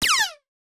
CD-ROOM/Assets/Audio/SFX/laser1.wav at main
laser1.wav